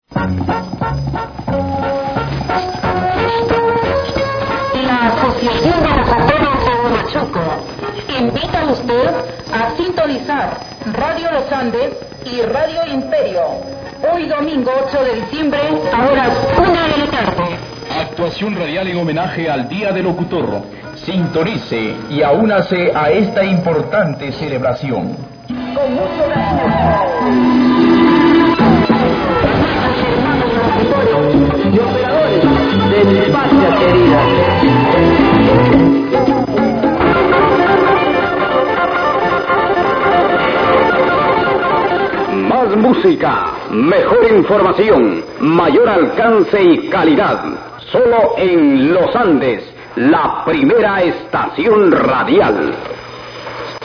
Peru on SW